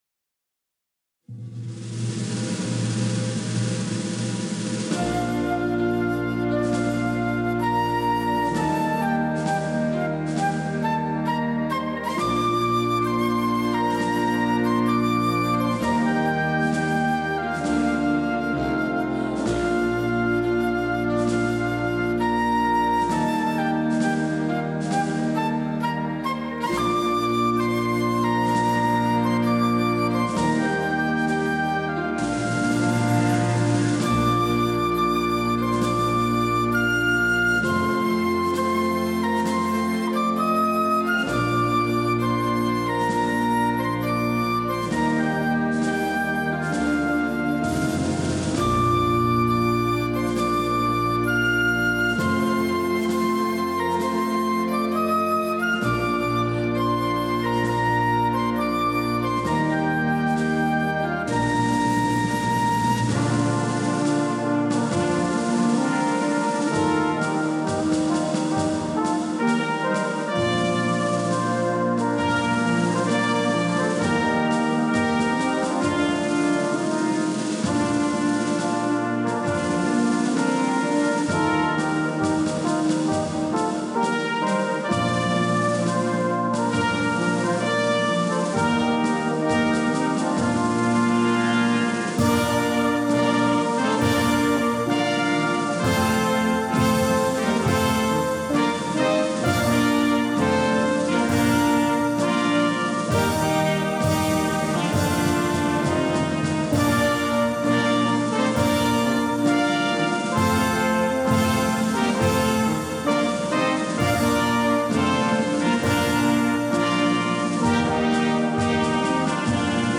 Gattung: Marsch
Besetzung: Blasorchester
Das Tempo entspricht mit =72 einem langsamen Schrittempo.